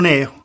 [nɛru]